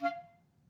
Clarinet
DCClar_stac_F4_v1_rr1_sum.wav